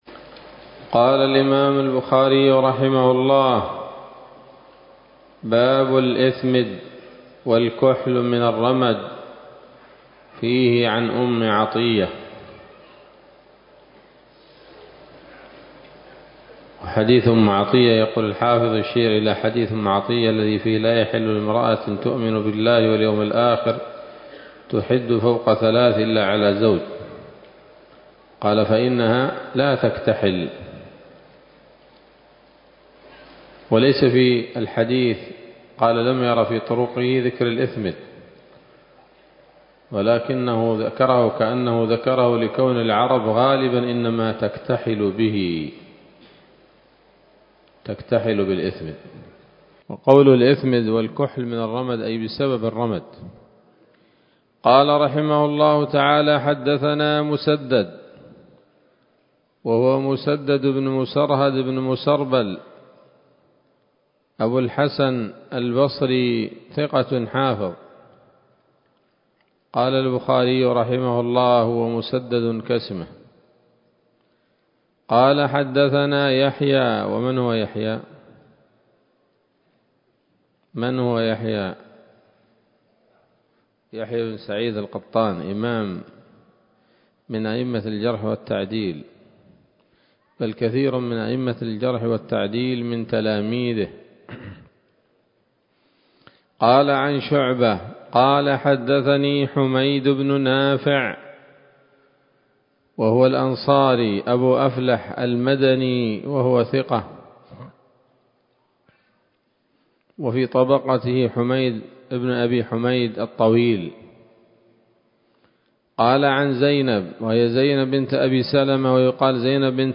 الدرس الرابع عشر من كتاب الطب من صحيح الإمام البخاري